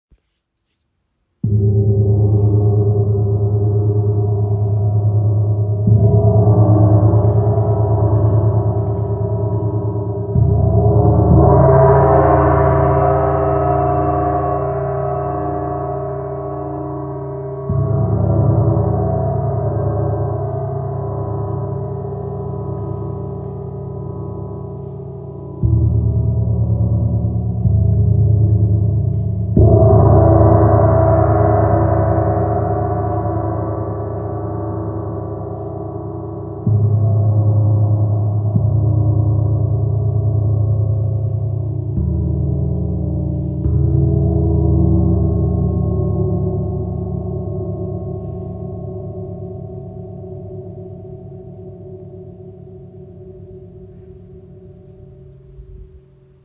Klangbeispiel
Sie erzeugen eine komplexe Klanglandschaft, die selbst durch sanfte Berührungen zum Klingen gebracht wird.